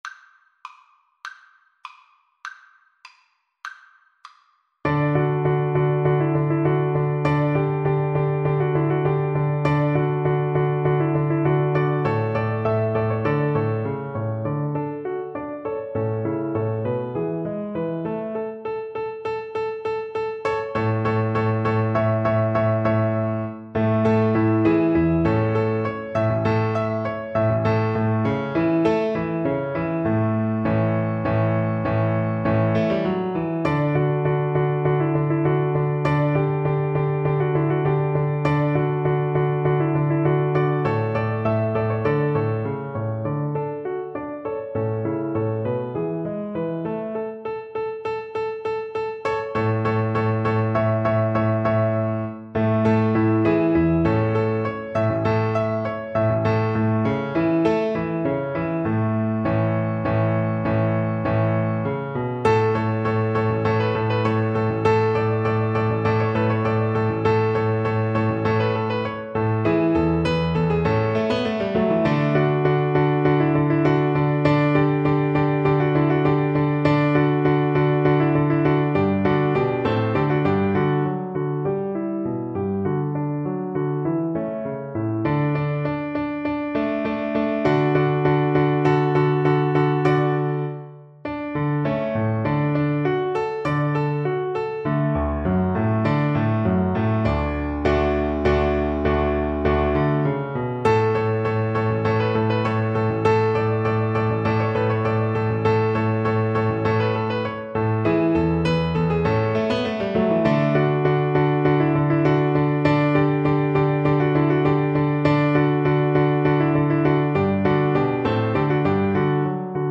2/4 (View more 2/4 Music)
~ = 100 Allegretto
Classical (View more Classical Violin Music)